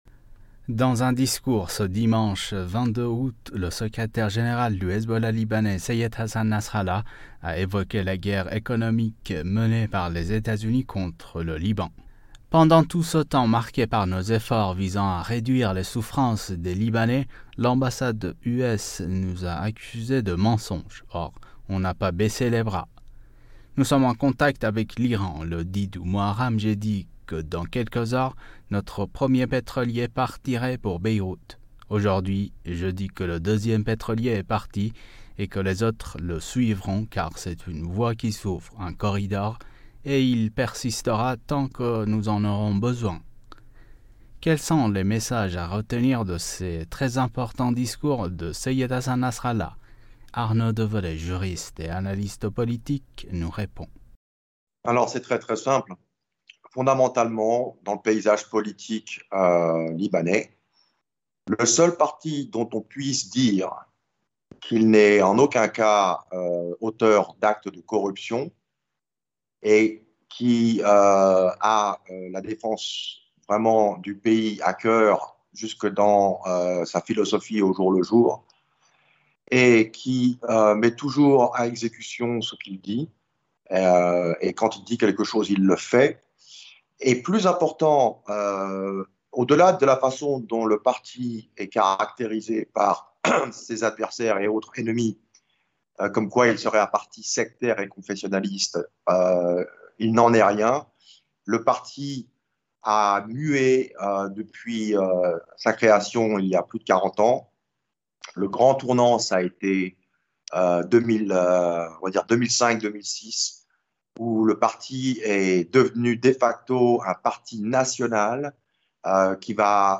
» Mots clés Iran Liban Nasrallah interview Eléments connexes Trump : pourquoi veut-il faire croire ses mensonges sur l’Iran au monde ?